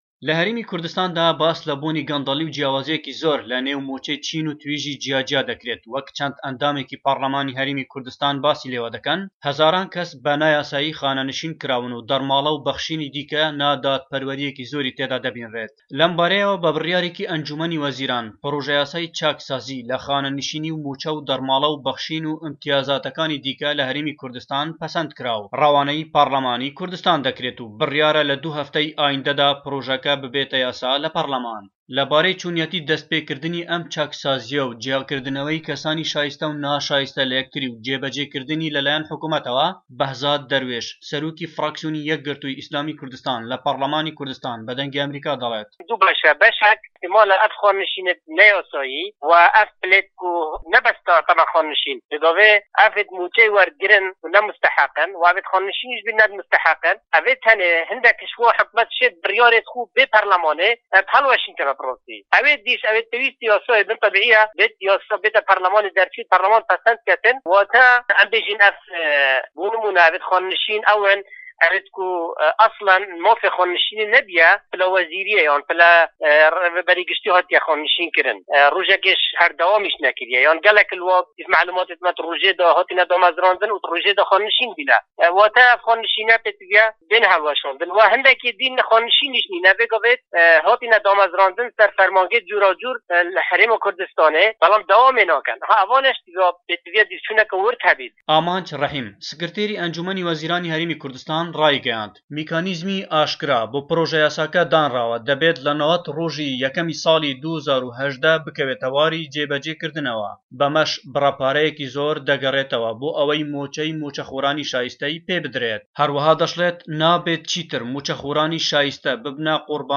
ڕاپۆرتی پاڕلمان